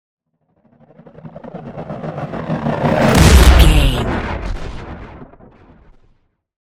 Dramatic chopper whoosh to hit
Sound Effects
Atonal
dark
intense
tension
woosh to hit